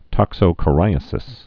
(tŏksō-kə-rīə-sĭs)